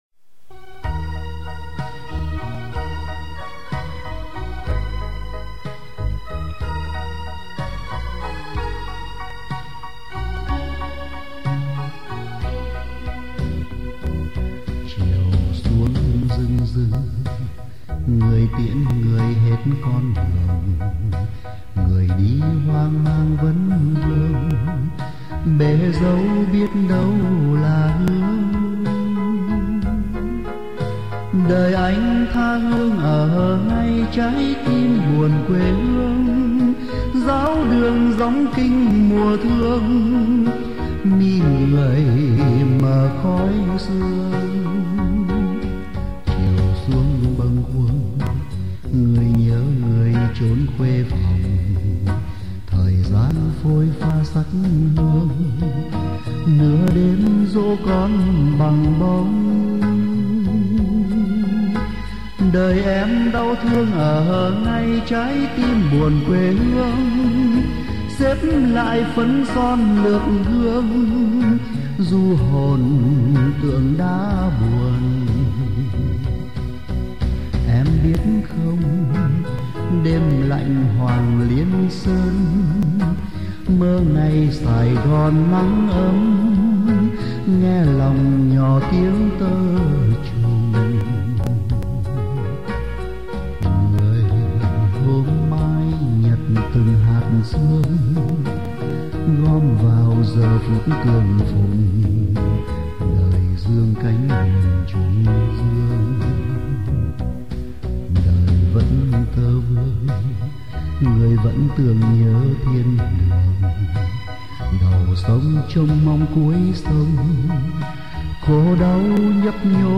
Tù khúc “Nhớ Cố Hương” là tâm sự của bất cứ người tù cải tạo nào.
Giọng hát dường như u uẩn hơn vì sau gần 10 năm “tha hương ở ngay trái tim buồn quê hương”, thì nay, sau gần 20 năm lưu lạc xứ người, tâm sự người tù năm xưa phải chăng là “Giữ gìn chút hương trầm thơm /Mơ về cố hương” như hai câu kết ngậm ngùi của bài tù khúc.